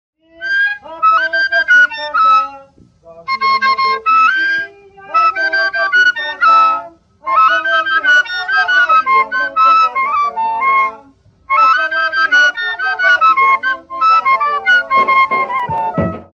Pífano
Flauta sem chaves confeccionada em materiais diversos e tocada transversal ou perpendicularmente. É utilizada nas bandas de pífanos e também nos reisados, em que é conhecida como gaita.
pifano.mp3